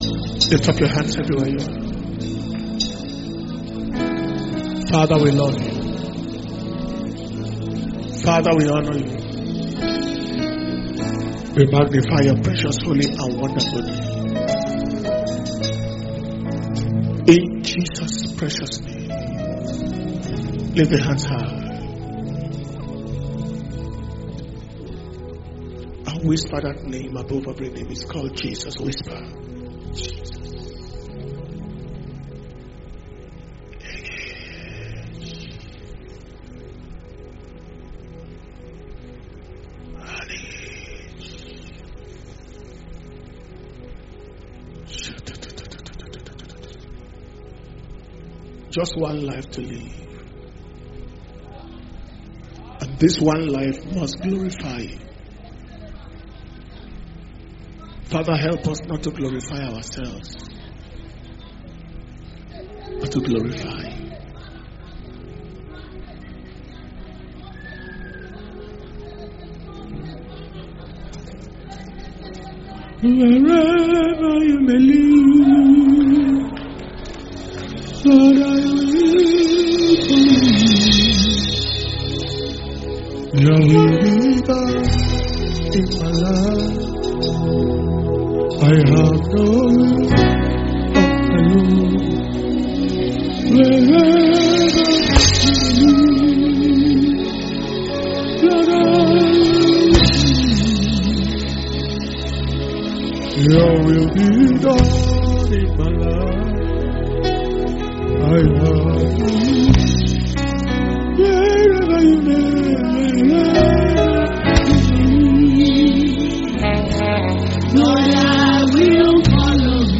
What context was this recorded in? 2021 Global Youth Convention Message